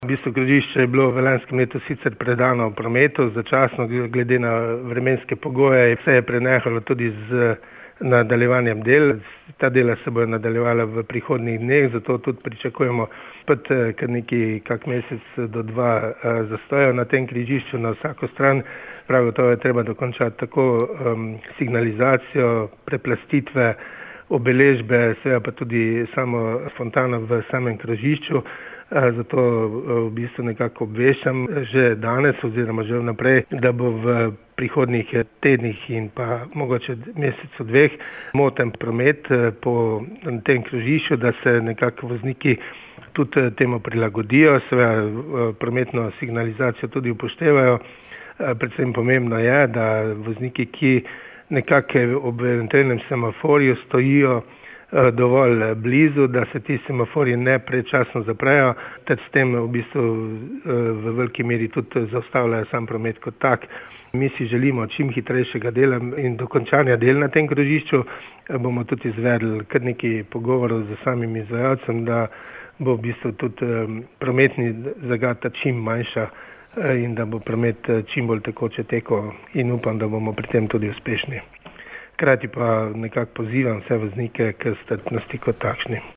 Čeprav je krožišče v Laškem odprto že od decembra, pa dela še niso povsem končana. Župan Franc Zdolšek je v pogovoru za Radio Kum povedal: